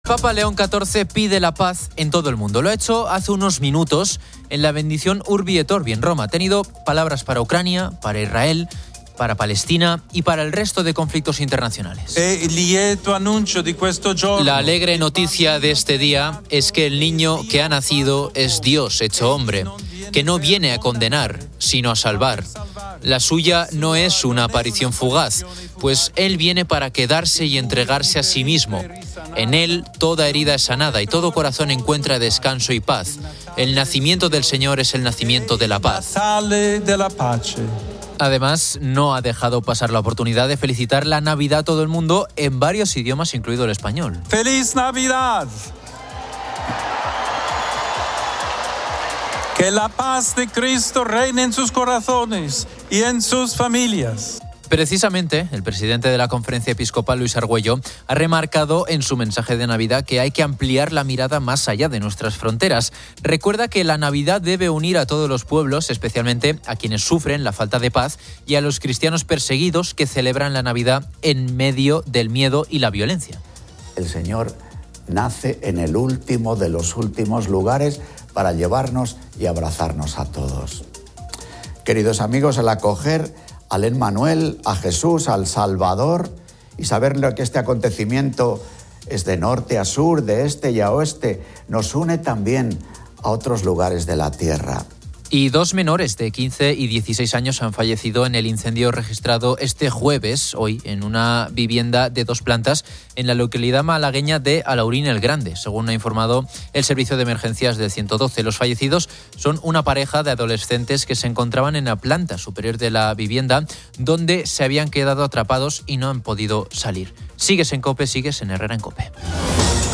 El programa musical celebra la Nochebuena y la Navidad, seleccionando canciones que buscan emocionar a los oyentes.